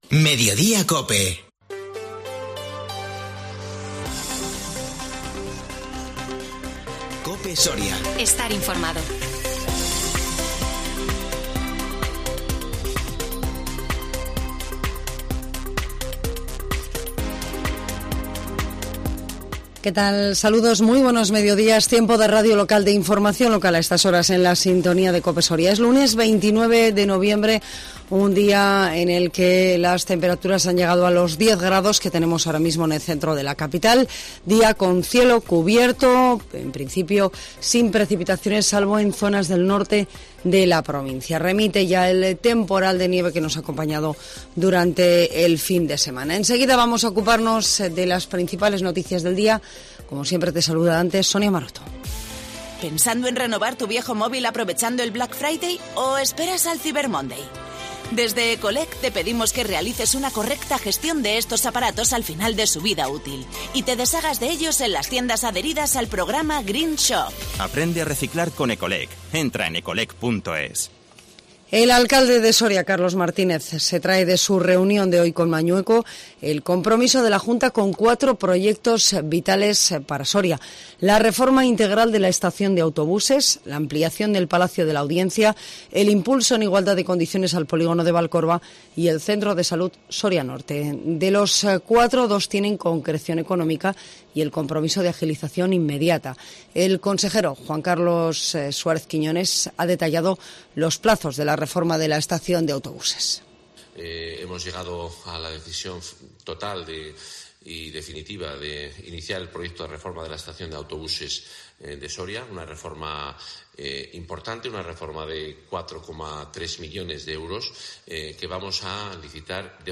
INFORMATIVO MEDIODÍA 29 NOVIEMBRE 2021